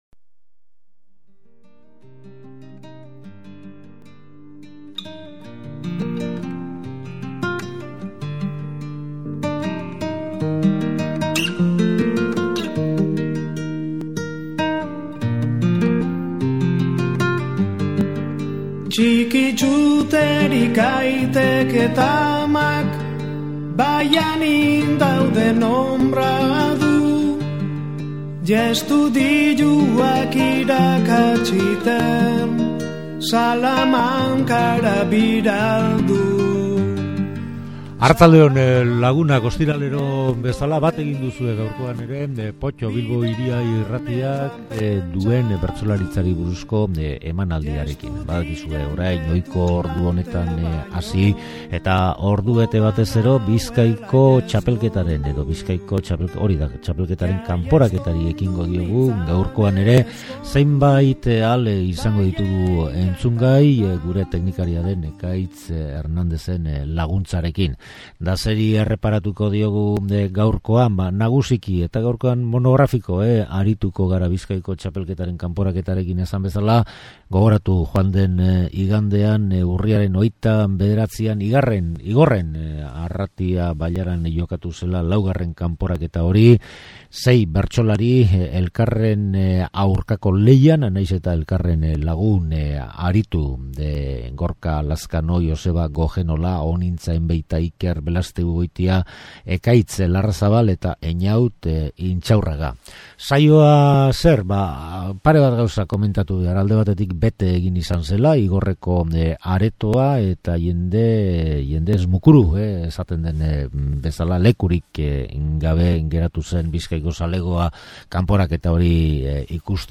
Joan den Domekan Igorren jokatu Bizkaiko txapelketaren 4. kanporaketari erreparatu diogu gaurkoan eta komentario gutxi egin dugunez, saioa ia bere osotasunean, hasi eta amaitu, entzuteko eskaini dugu.